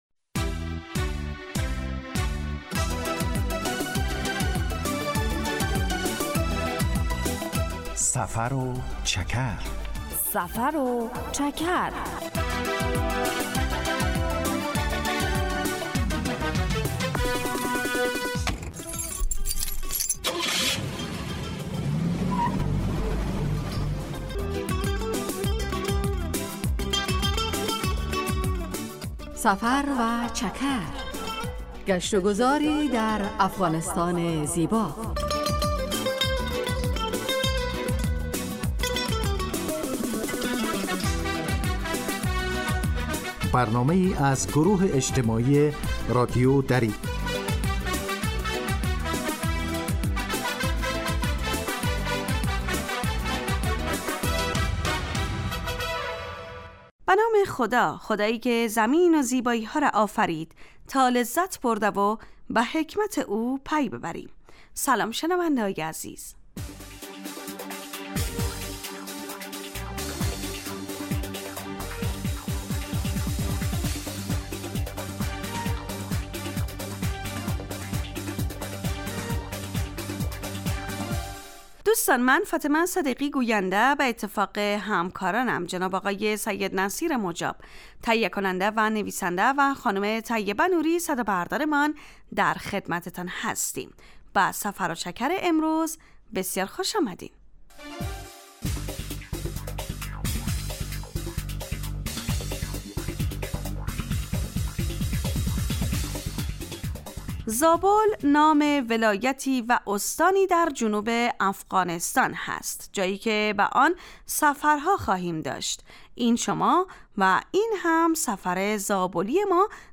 در سفر و چکر ؛ علاوه بر معلومات مفید، گزارش و گفتگو های جالب و آهنگ های متناسب هم تقدیم می شود.